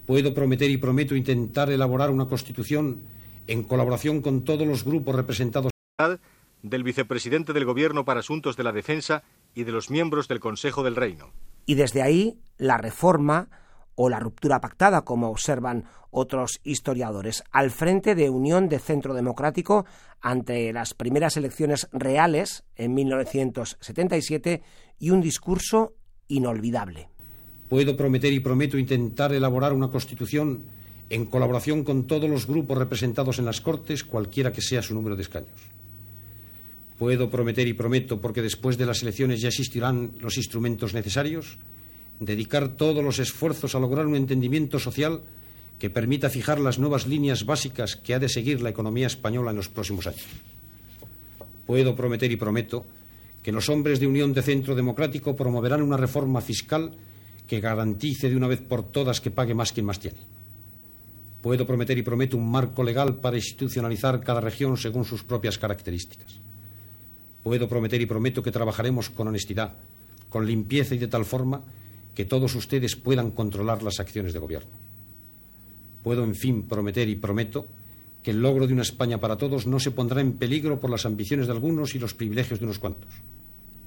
Paraules del candidat Adolfo Suárez, d'Unión de Centro Democrático (UCD), en la seva darrera al·locució abans de les primeres eleccions democràtiques, després de la mort de Francisco Franco, celebrades el 15 de juny de 1977